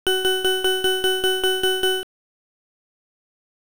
Note that there are three versions of each file - the original sound from the Pac-Man hardware, in both mono and stereo, and a generated version.
Synthesize the Extra Life (extralife.wav) sound.
extralife.wav